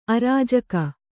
♪ arājaka